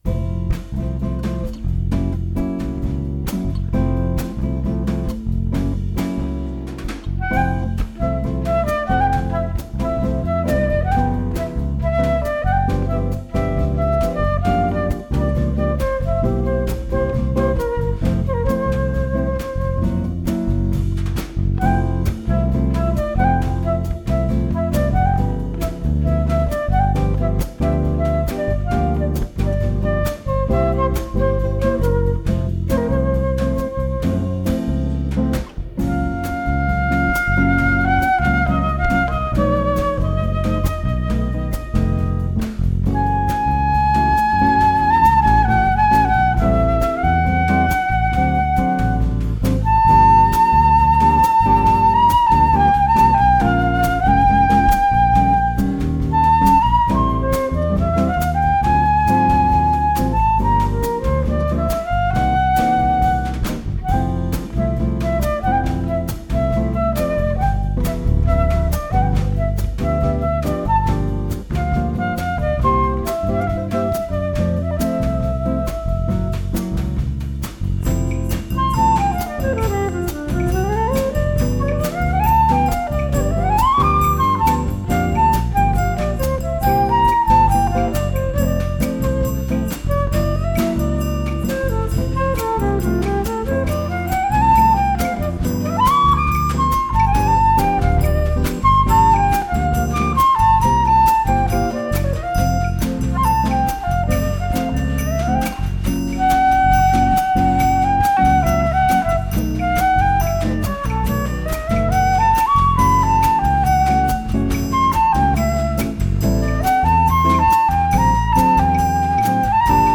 percussion
bass
acoustic rhythm and solo electric guitar
flute and percussion